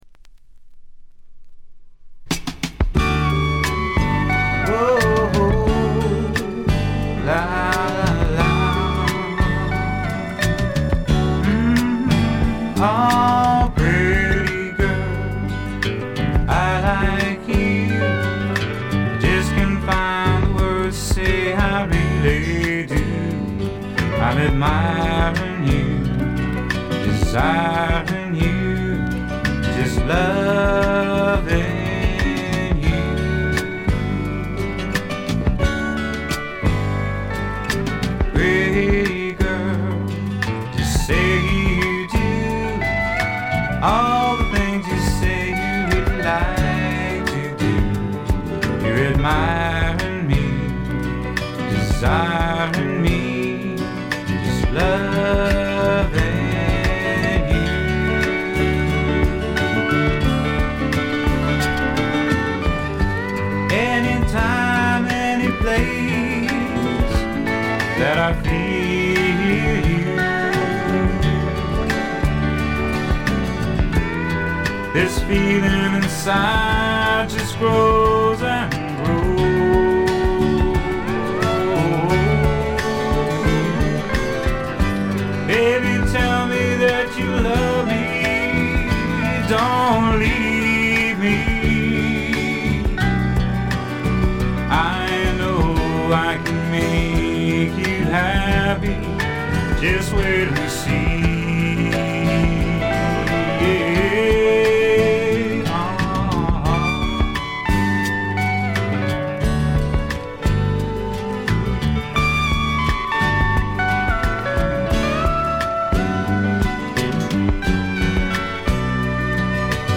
部分試聴ですがわずかなノイズ感のみ。
潮風に乗せたちょっとフォーキーなAORといったおもむきが心地よいです。
試聴曲は現品からの取り込み音源です。